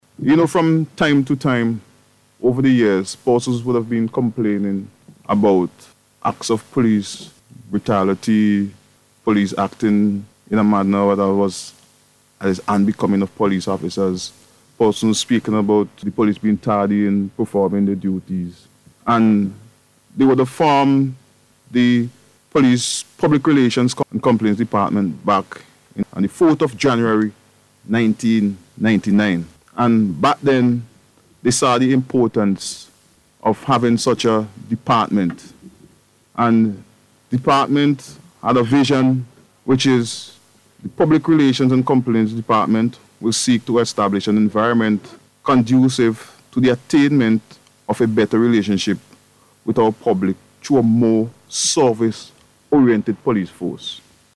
On the Beat program aired on NBC Radio.